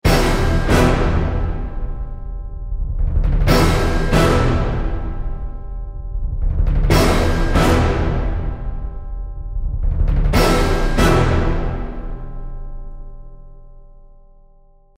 First of all, I’ll boost my low-end drums (the gran casa). Also, I decided that in this hit I would like to hear mostly low-end instruments and mainly our E and B tones.
OrchestralHits_Final.mp3